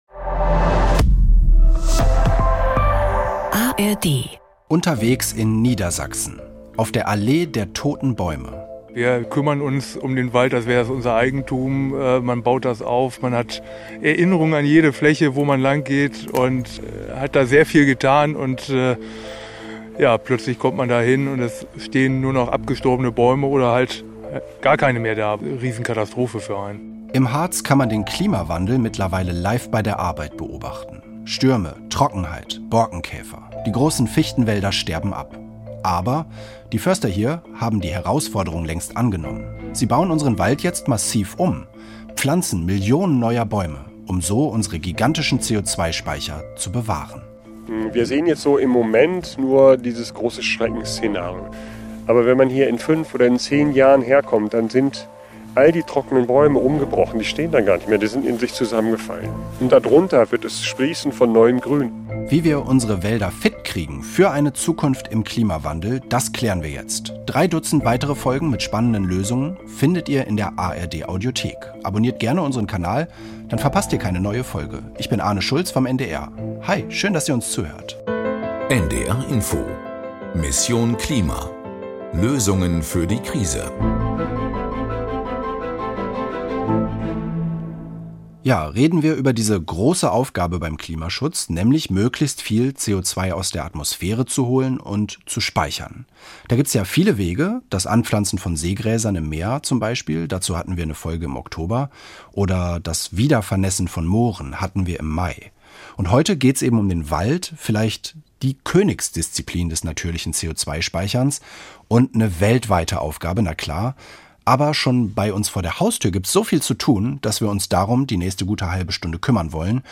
Dort war sie unterwegs mit Mitarbeitern der niedersächsischen Landesforsten.